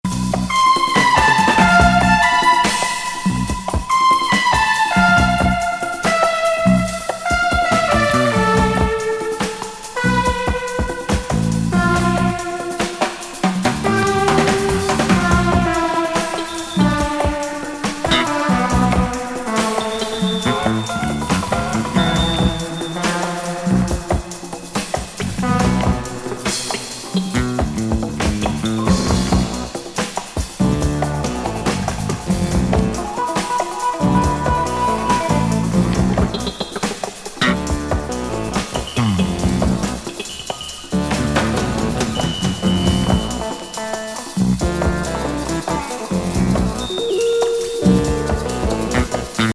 メンツ参加の76年ライブ録音!壮大な展開のセッション。